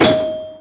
bumperding1.wav